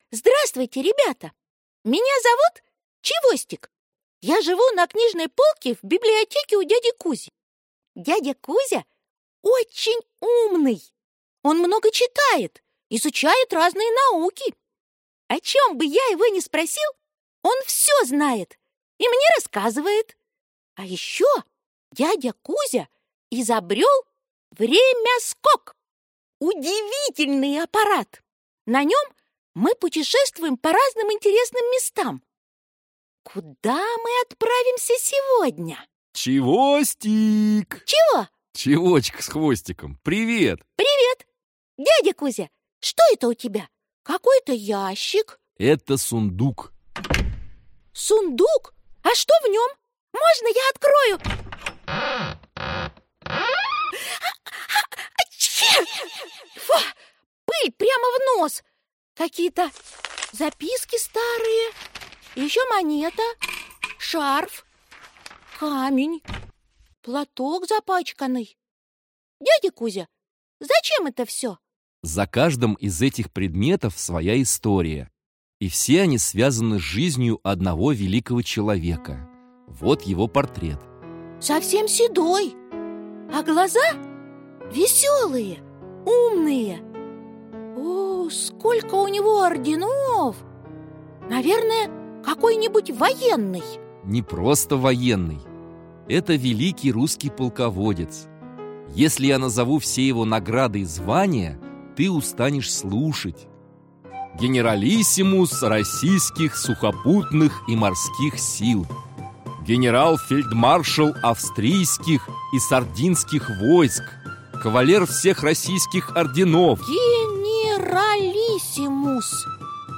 Аудиокнига Великие люди. А.В.Суворов | Библиотека аудиокниг
А.В.Суворов Автор Детское издательство Елена Читает аудиокнигу Актерский коллектив.